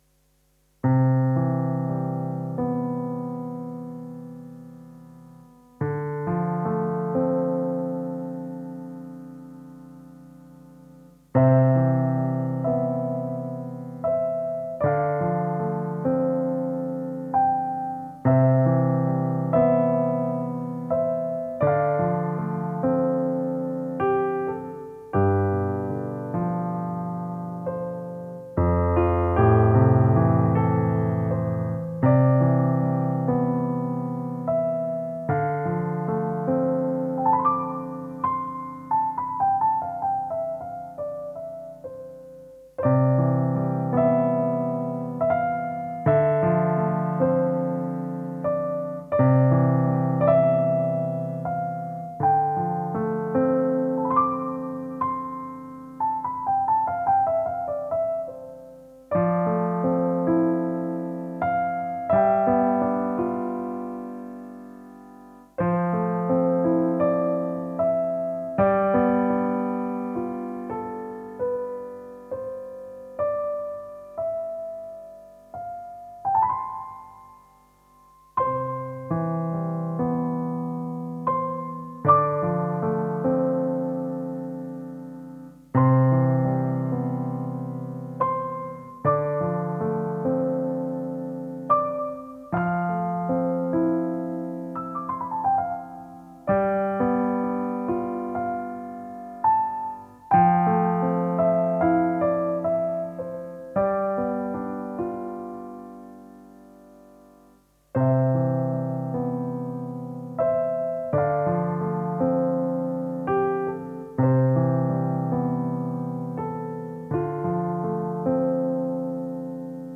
Relaxtes Lounge Piano.